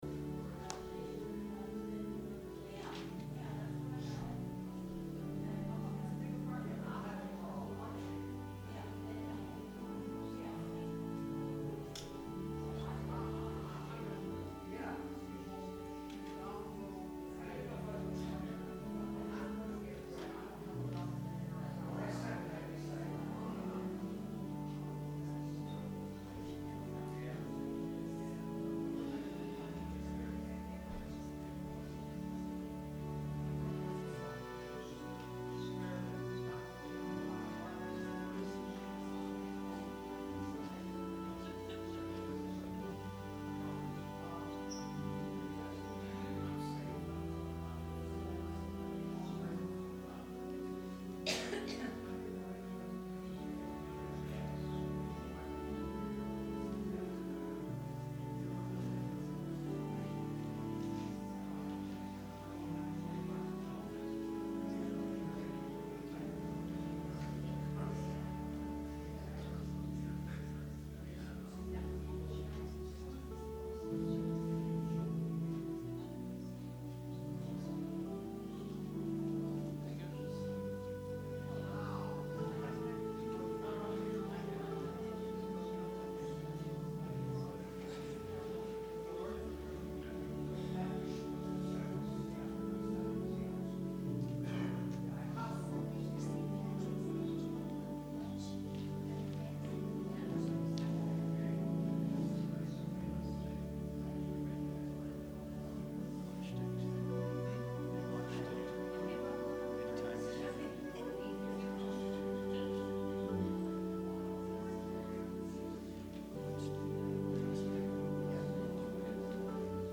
Sermon – March 10, 2019 – Advent Episcopal Church